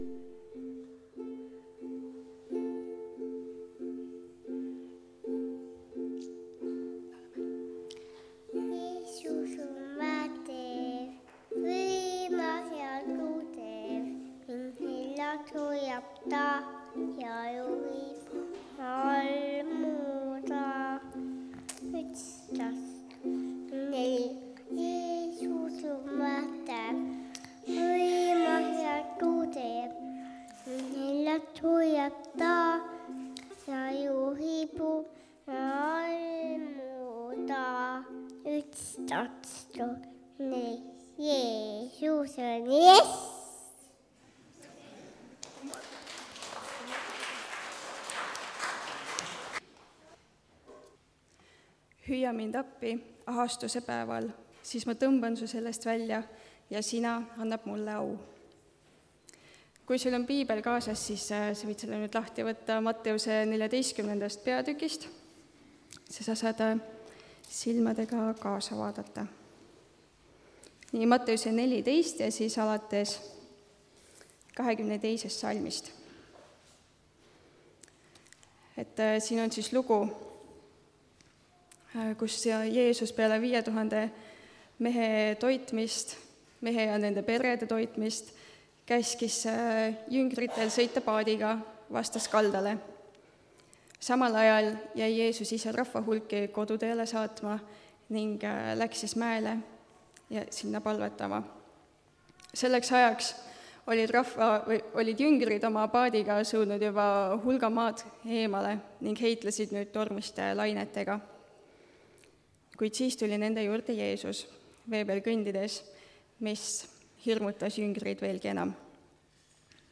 Tunnistuste koosolek (Tallinnas)